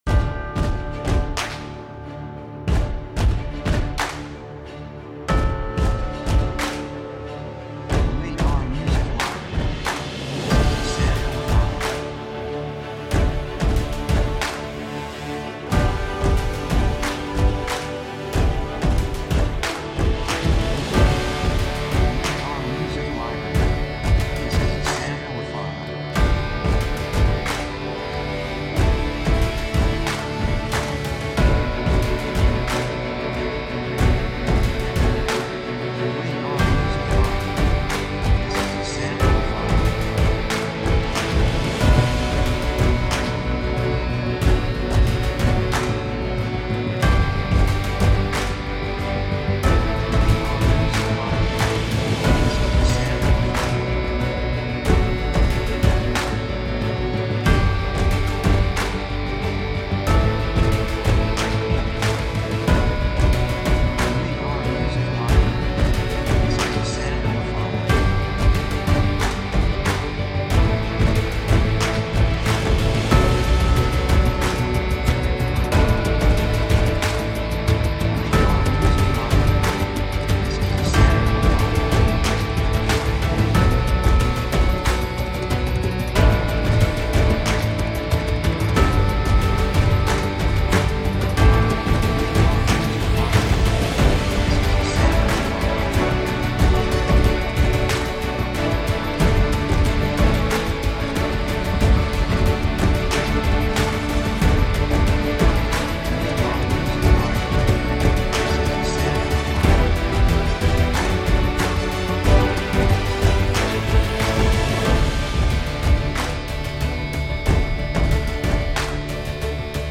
雰囲気高揚感, 決意, 喜び
曲調ポジティブ
楽器エレキギター, パーカッション, ストリングス, ボーカル, 手拍子
サブジャンルアクション, オーケストラハイブリッド
テンポミディアム